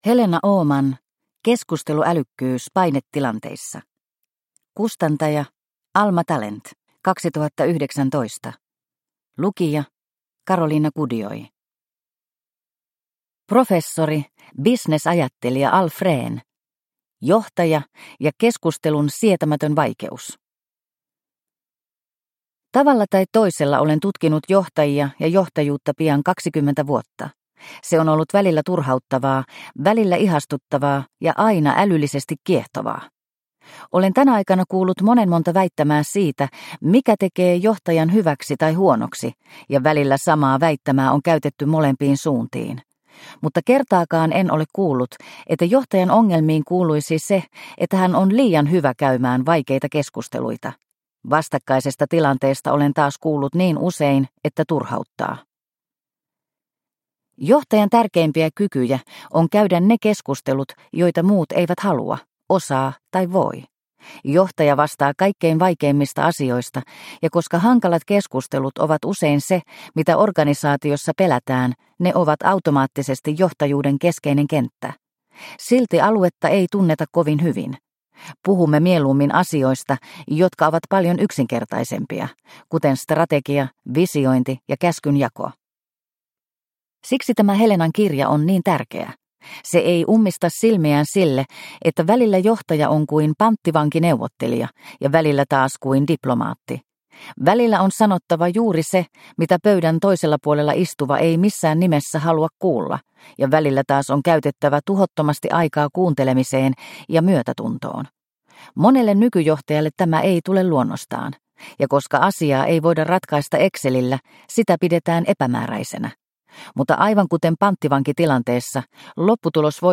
Keskusteluälykkyys painetilanteissa – Ljudbok – Laddas ner